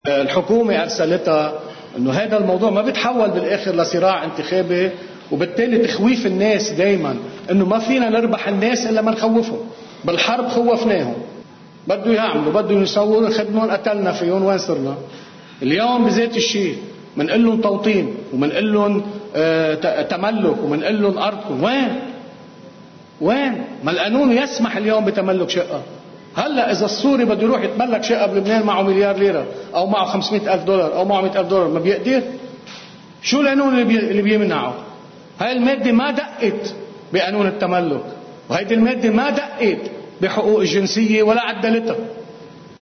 مقتطف من حديث أمين سر تكتل التغيير والإصلاح النائب كنعان بعيد اجتماع التكتّل: (الجزء 1- 10 نيسان 2018)